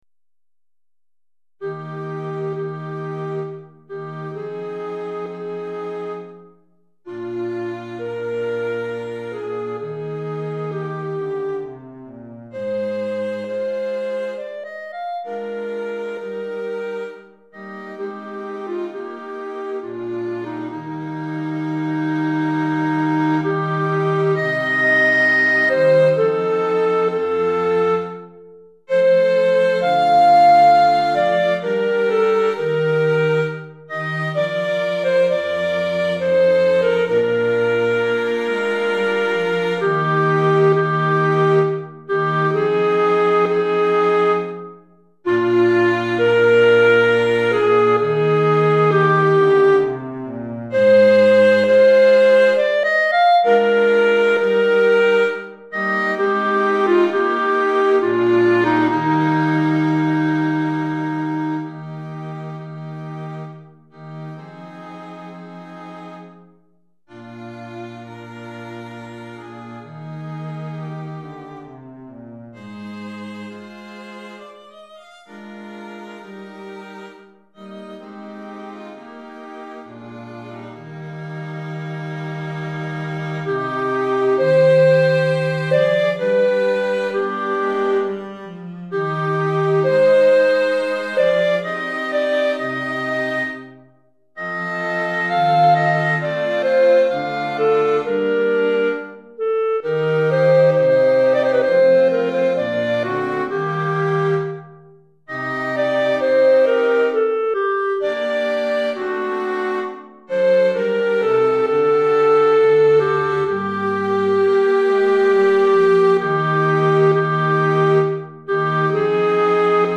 Clarinette et Quatuor à Cordes